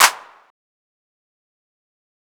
TS Clap 4.wav